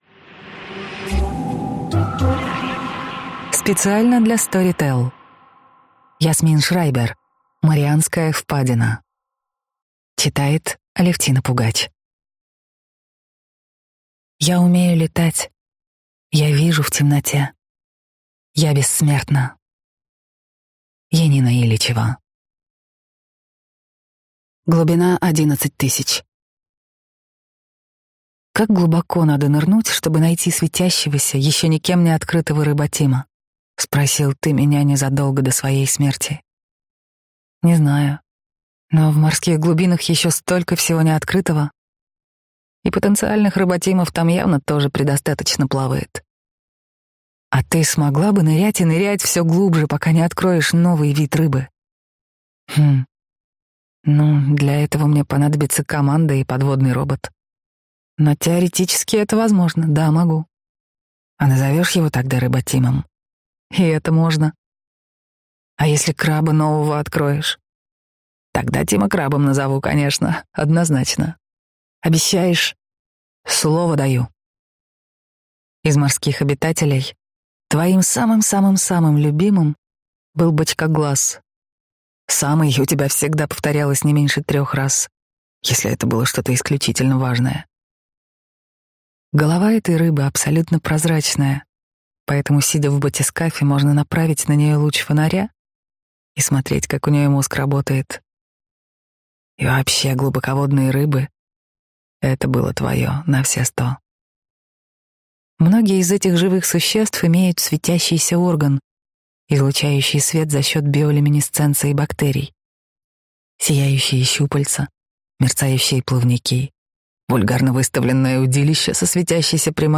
Аудиокнига Марианская впадина | Библиотека аудиокниг